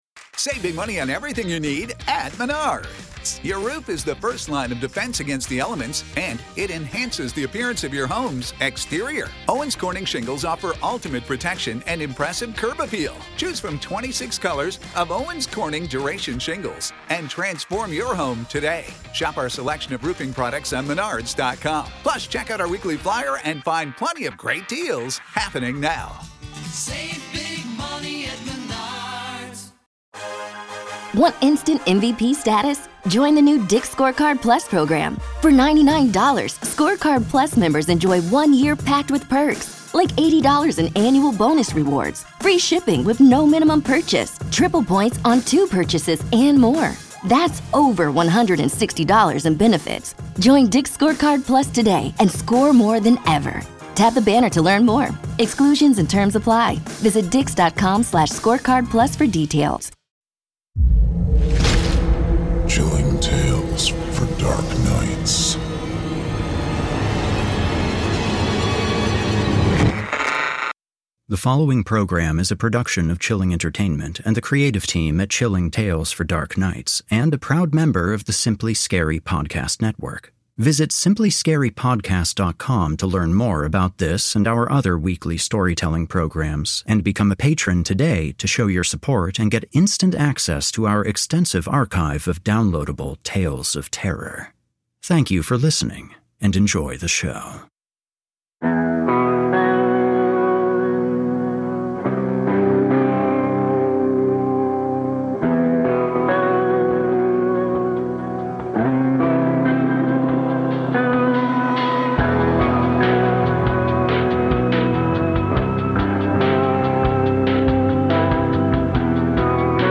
Horror Hill — A Horror Fiction Anthology and Scary Stories Series Podcast / S12E04 - "The Hidden Truth" - Horror Hill